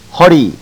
To hear proper pronunciation, click one of the links below
Ho Ree -Waist